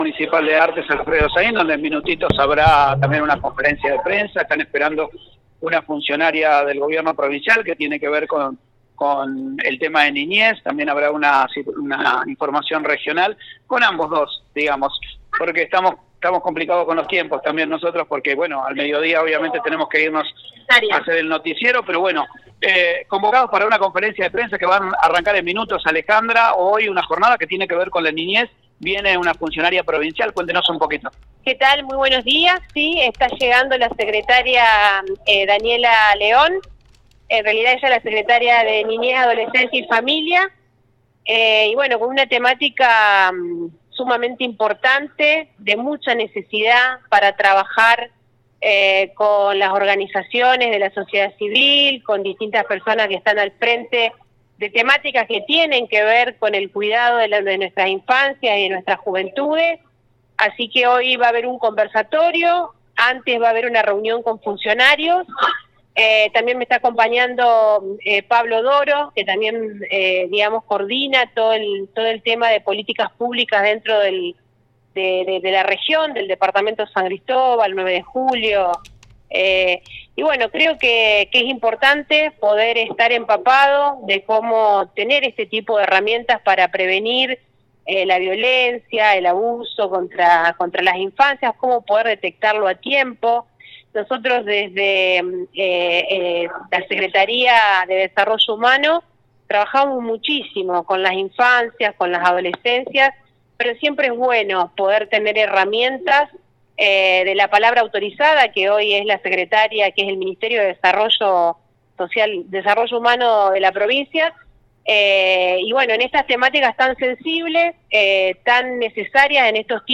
en la cobertura que realizamos en vivo por FM Ceres 98.7 Mhz. pudimos dialogar con la Intendente Alejandra Dupouy, junto la Sub Secretaria de Niñez Marilina Grande y las distintas funcionarias provinciales.
Este lunes pasadas las 11.30 horas en el Liceo Municipal se realizó una conferencia de prensa junto a la Secretaría de los Derechos de la Niñez, Adolescencia y Familia de la Provincia a cargo de Daniela León.